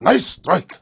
zen_nicestrike.wav